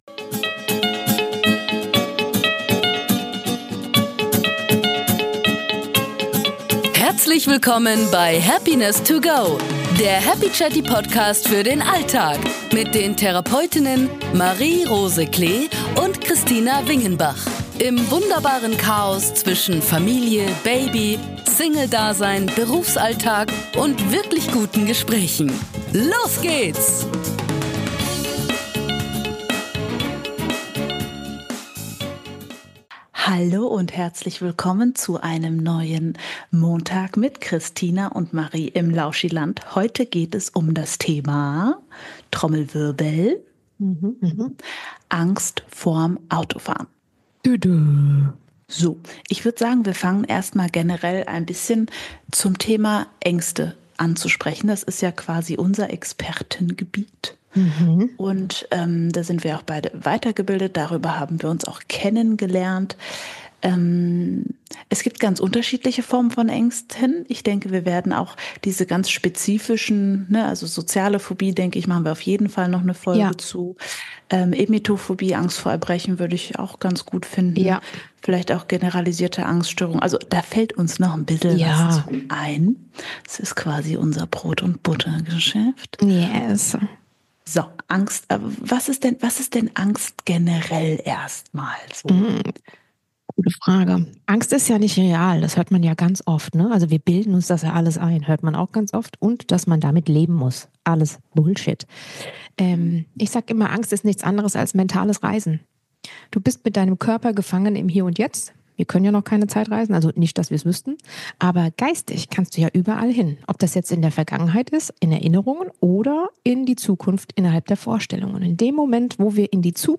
In "Happiness to go" nehmen euch zwei Therapeutinnen mit auf eine Reise durch die Höhen und Tiefen des Alltags und der Therapie.